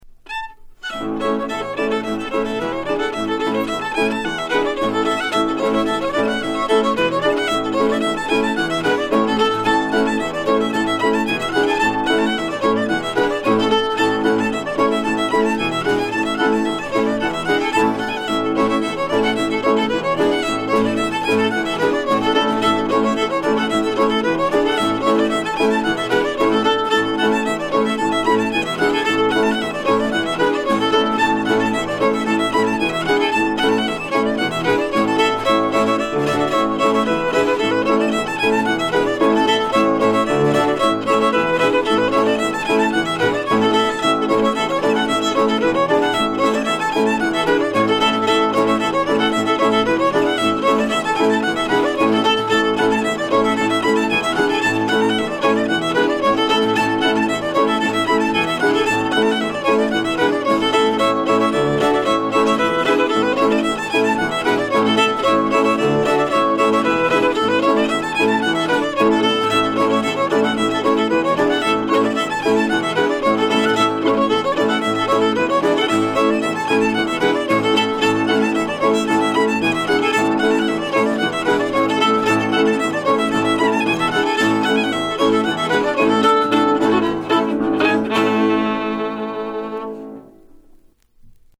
Recorded on September 25, 1975 in Stowe, Vermont
fiddle
piano
guitar